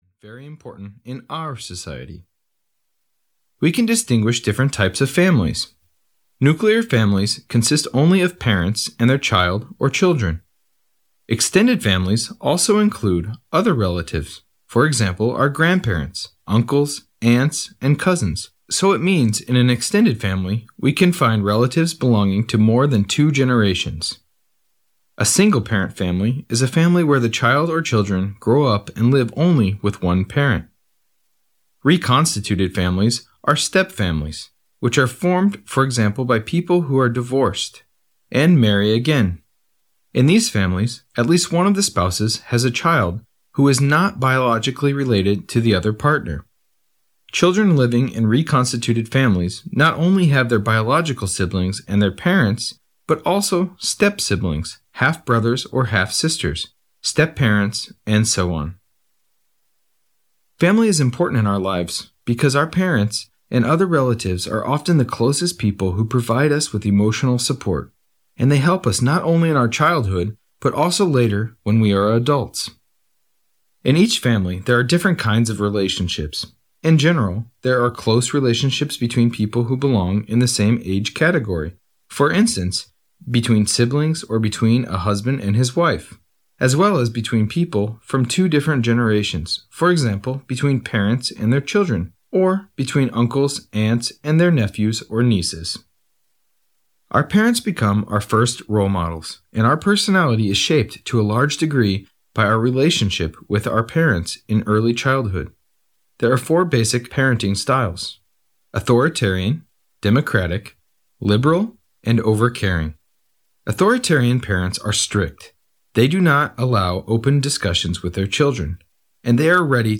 Ukázka z knihy
vďaka čomu má ľahko zrozumiteľný, takpovediac neutrálny prízvuk.